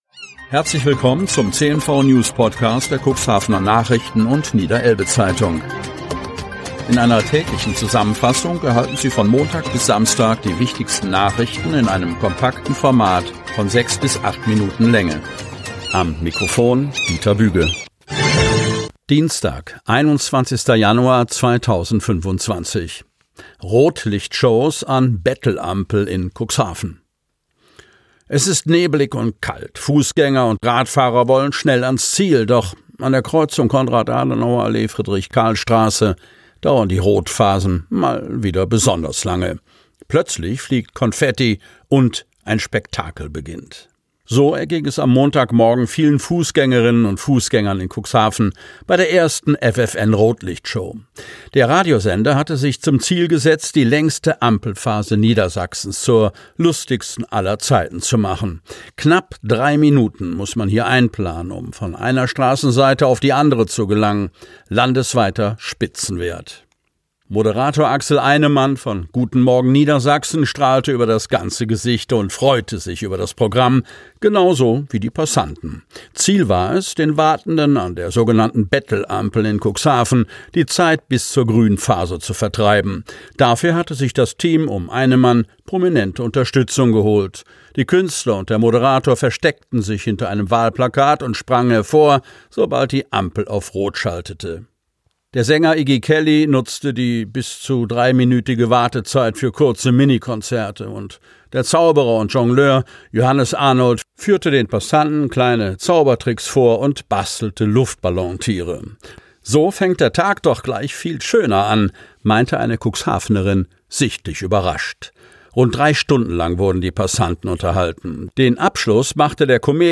Ausgewählte News der Cuxhavener Nachrichten + Niederelbe-Zeitung am Vorabend zum Hören!